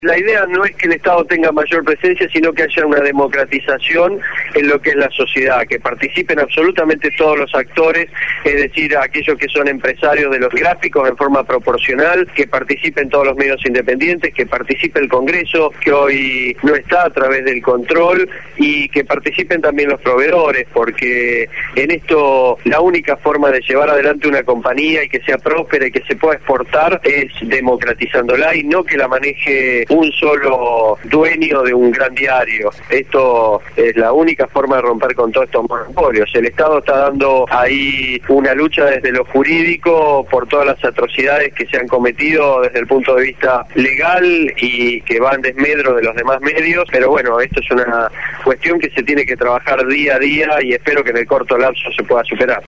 Daniel Reposo, Titular de la SIGEN «Sindicatura General de la Nacion»